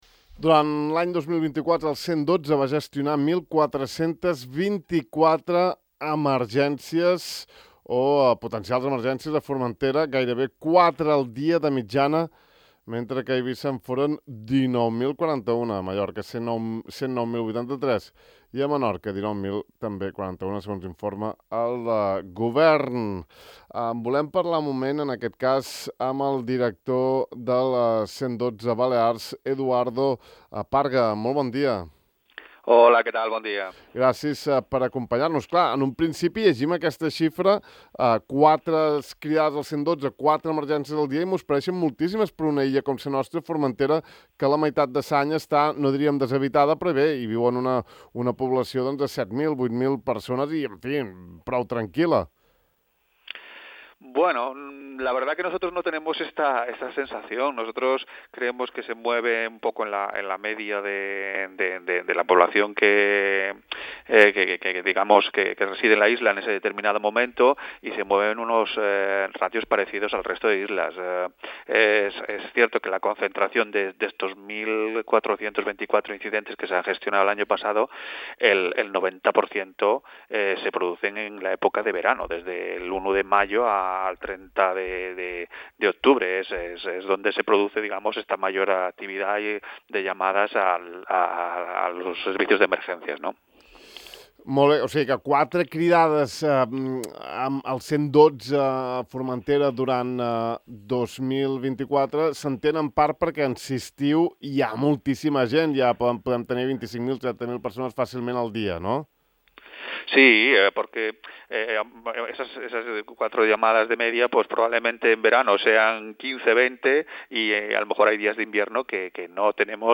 Parlem amb el director del Centre d’Emergències del 112 de les Illes Balears, Eduardo Parga, després de conèixer les dades d’incidències de l’any 2024.